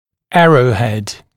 [‘ærəuˌhed][‘эроуˌхэд]стреловидная часть кламмера